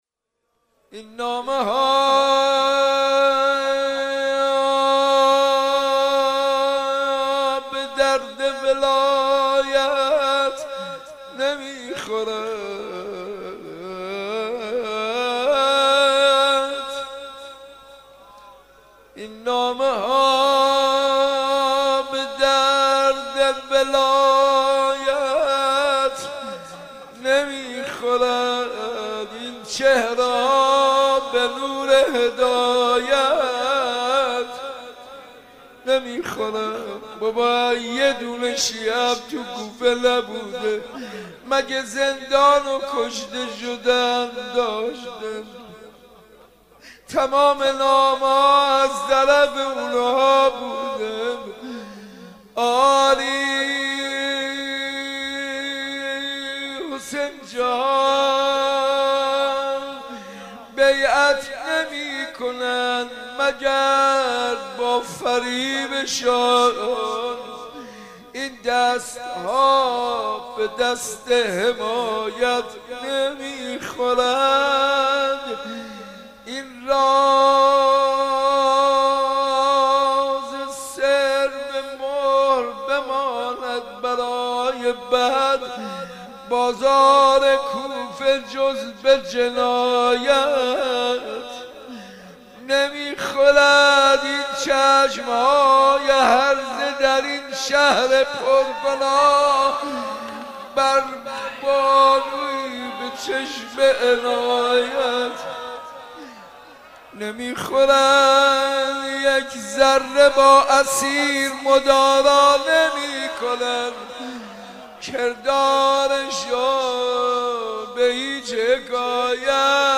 روضه خوانی و سینه زنی در شهادت حضرت مسلم بن عقیل سلام الله علیه
روضه خوانی در شهادت حضرت مسلم- حاج منصور ارضی.mp3